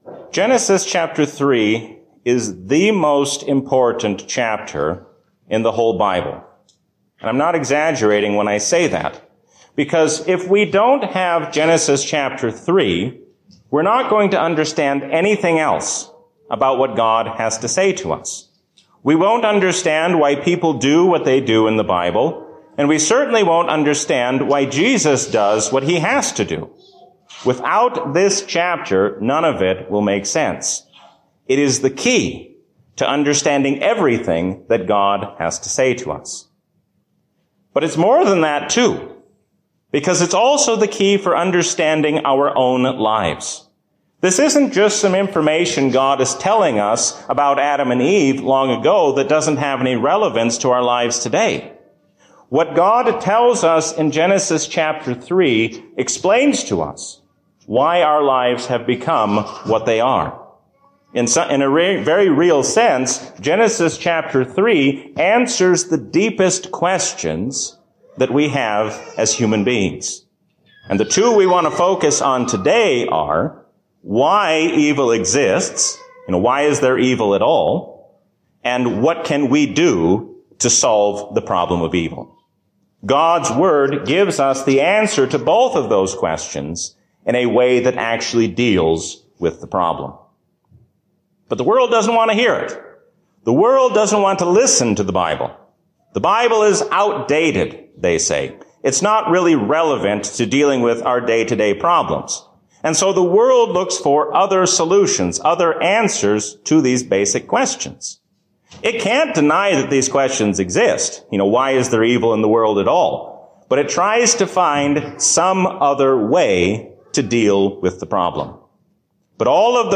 A sermon from the season "Lent 2021." God gives us hope in His Son even when the future seems uncertain.